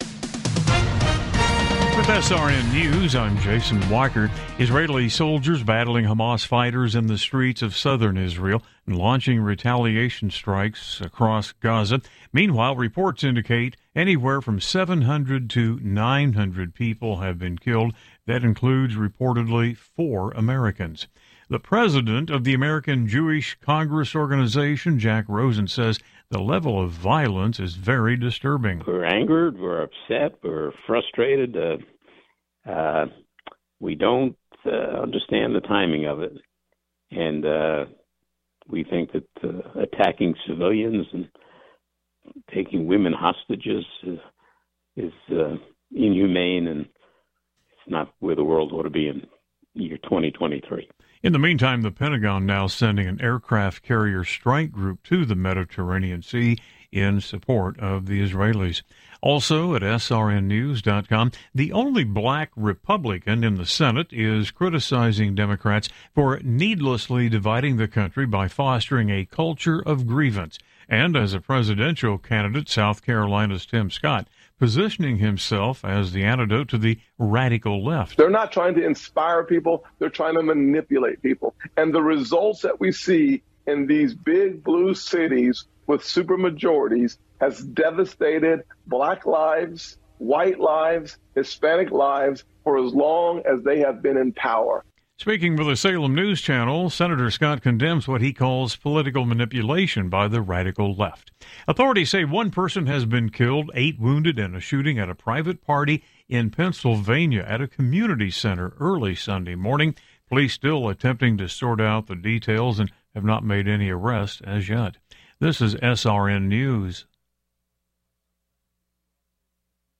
Top News Stories Oct 08, 2023 – 07:00 PM CDT